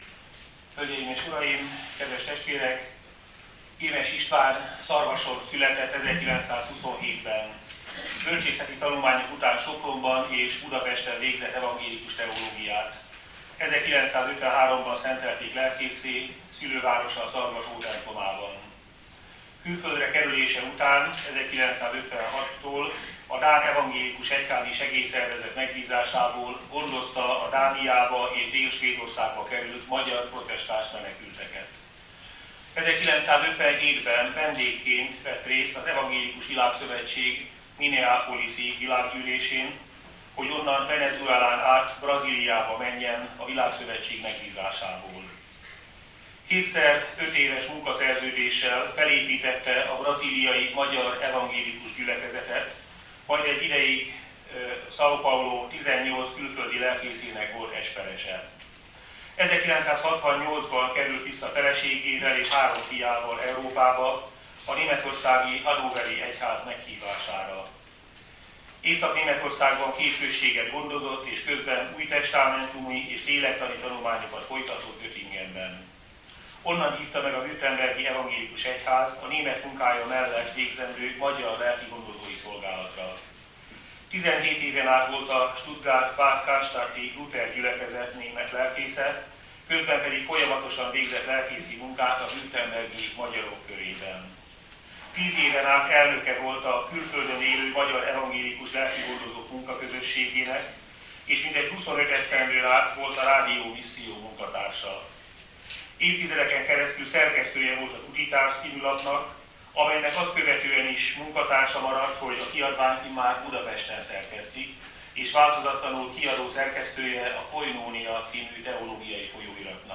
Budapest – 2011. február 24-én az Evangélikus Hittudományi Egyetemen ünnepi konferenciát rendeztek, amelynek második részében ünnepélyes díszdoktoravatásra került sor.
laudációja